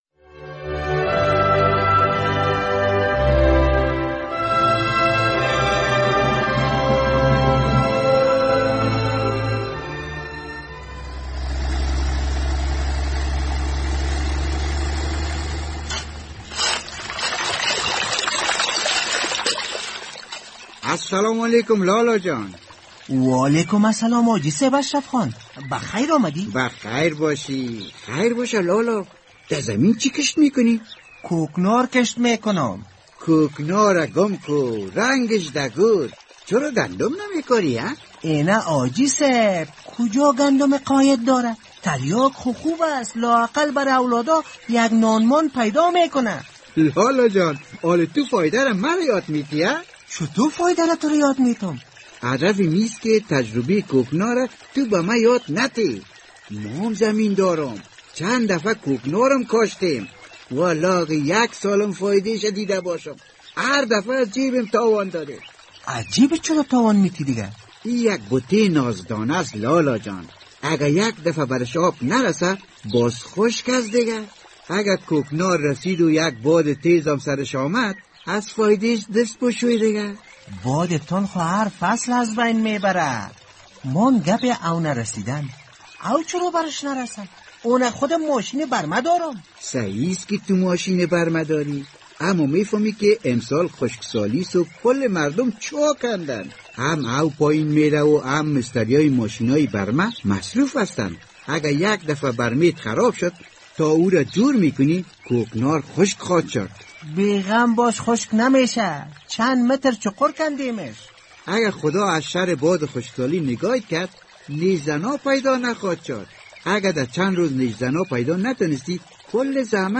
برای شنیدن این درامه روی لینک زیر کلیک کنید!